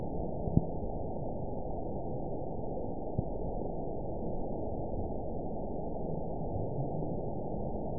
event 921991 date 12/24/24 time 20:47:53 GMT (11 months, 1 week ago) score 8.71 location TSS-AB04 detected by nrw target species NRW annotations +NRW Spectrogram: Frequency (kHz) vs. Time (s) audio not available .wav